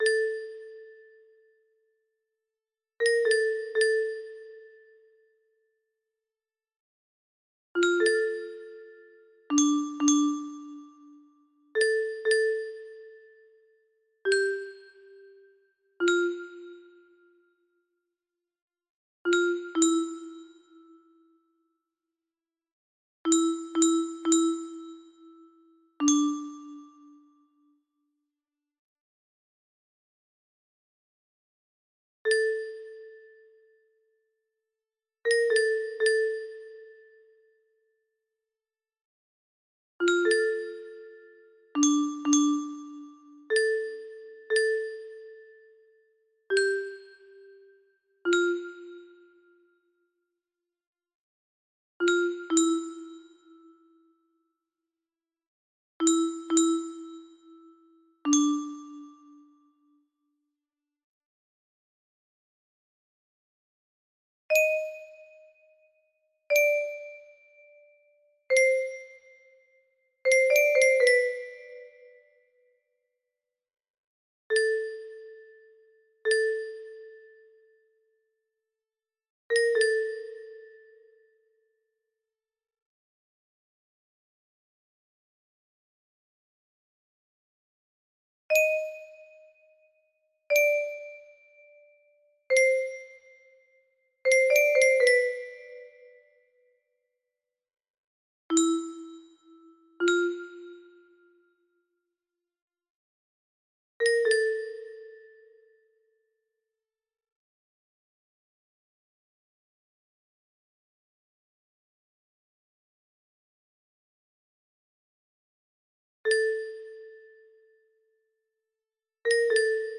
Vocal part, planned to sing :)